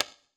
surface_wood_tray5.mp3